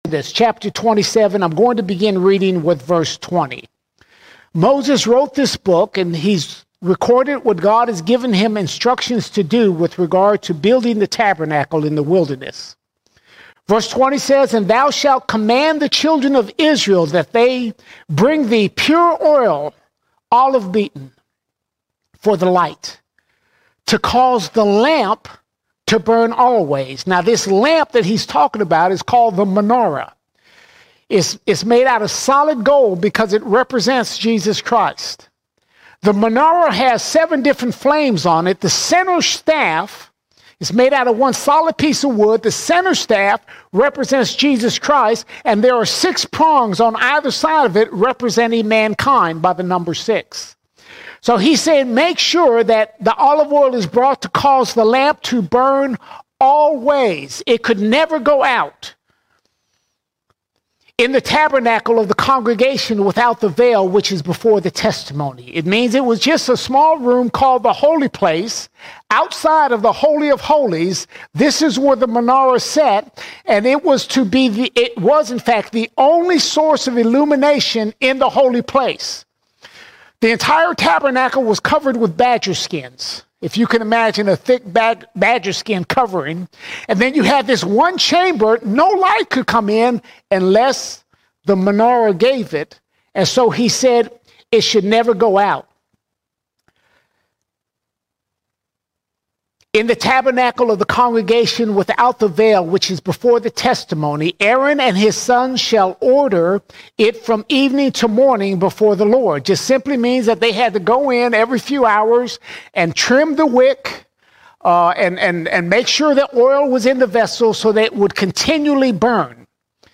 10 February 2025 Series: Sunday Sermons All Sermons The Spirit Is Light The Spirit Is Light Fellowship with the Holy Spirit is light and life for the believer!